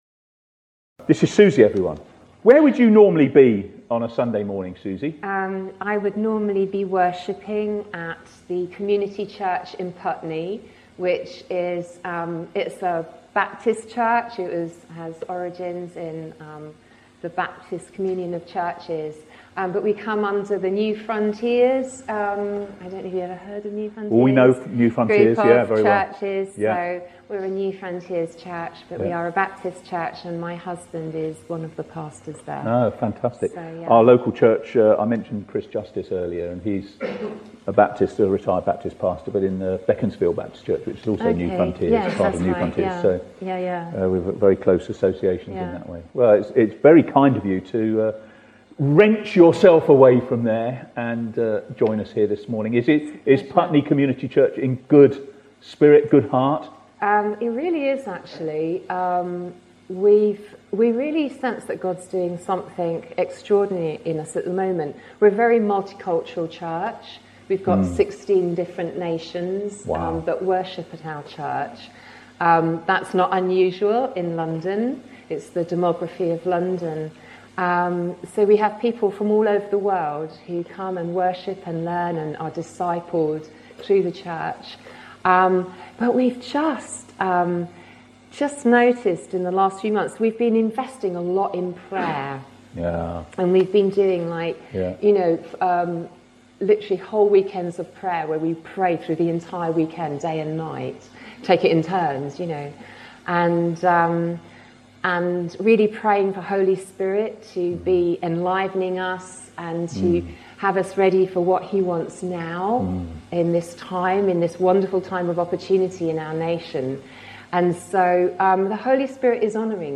Living in the presence and power of the Holy Spirit | SGBC